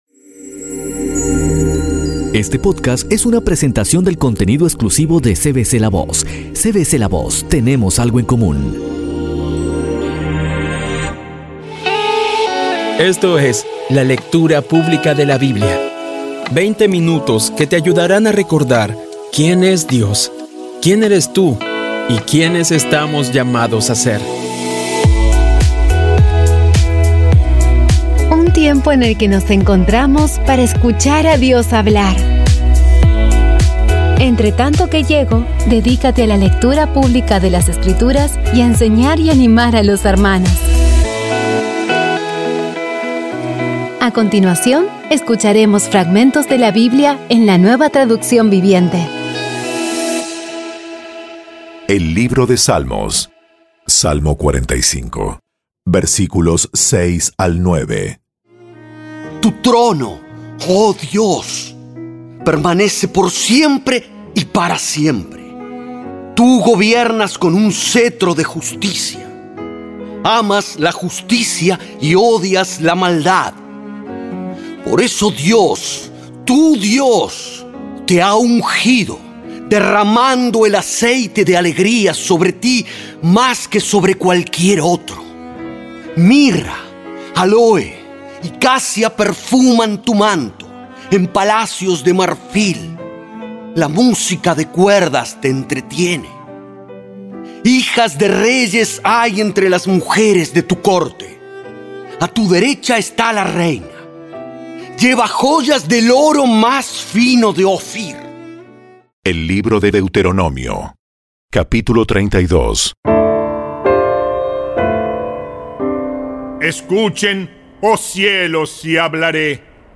Audio Biblia Dramatizada Episodio 101
Poco a poco y con las maravillosas voces actuadas de los protagonistas vas degustando las palabras de esa guía que Dios nos dio.